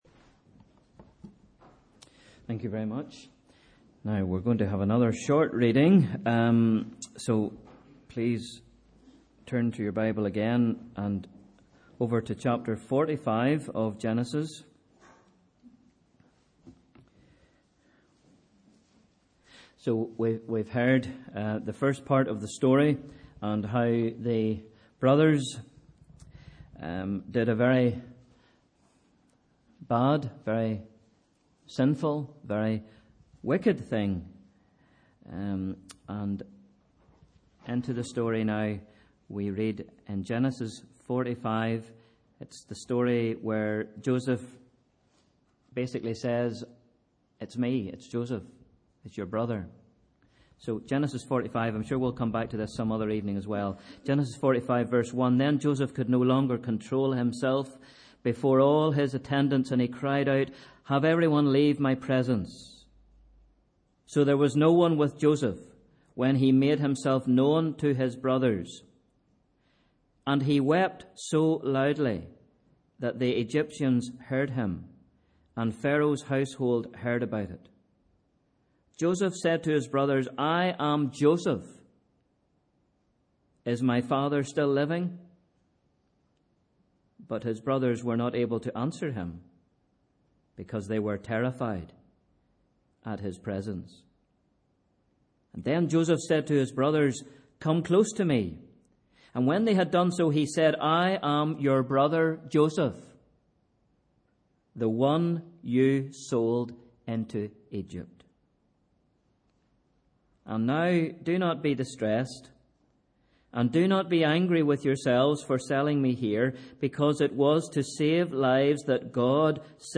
Sunday 8th November 2015 – Evening Service